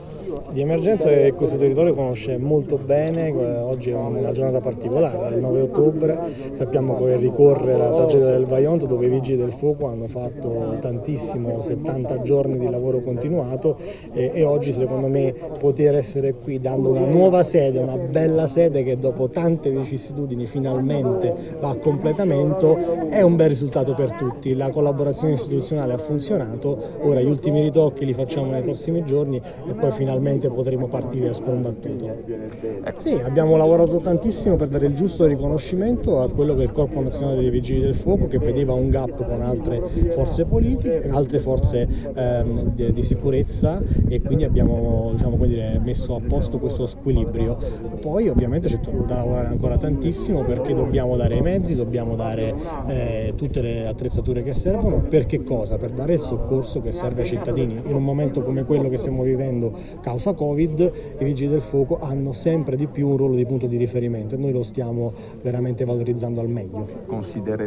ALCUNI INTERVENTI IN OCCASIONE DELL’INAUGURAZIONE DELLA NUOVA SEDE DI VIGILI DEL FUOCO, COMANDO DI BELLUNO.